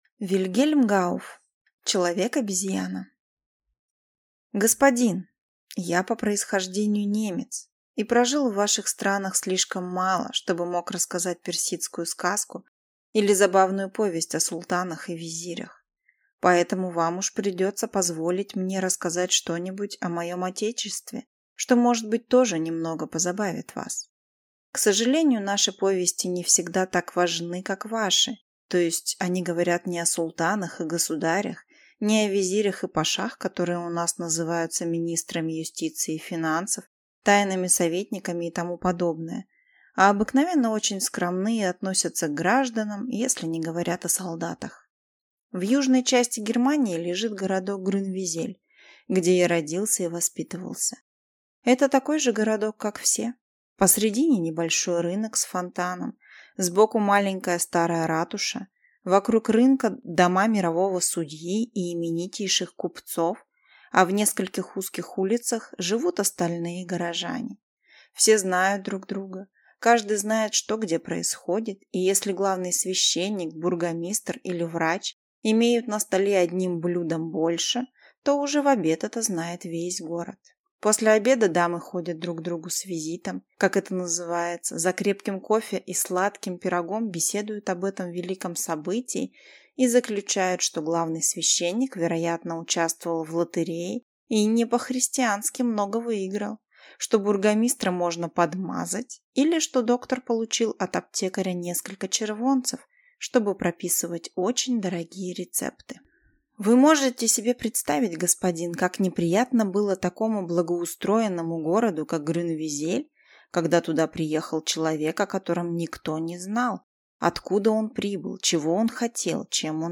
Аудиокнига Человек-обезьяна | Библиотека аудиокниг